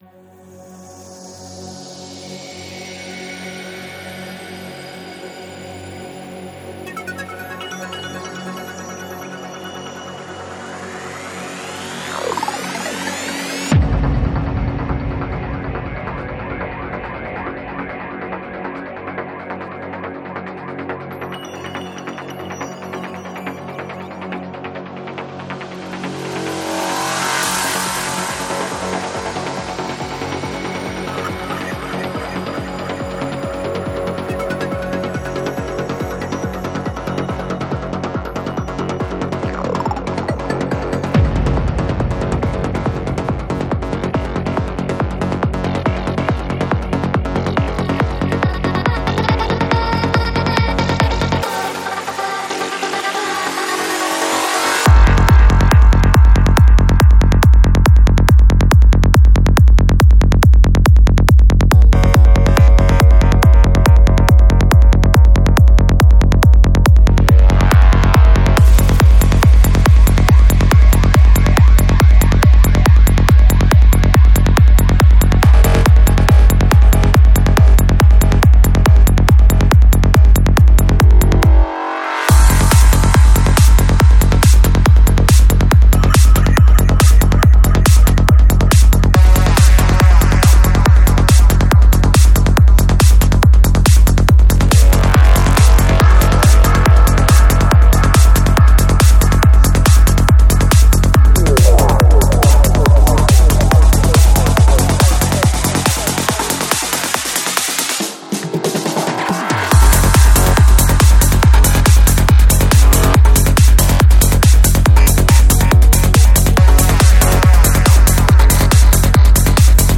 Жанр: Psychedelic
19:50 Альбом: Psy-Trance Скачать 7.52 Мб 0 0 0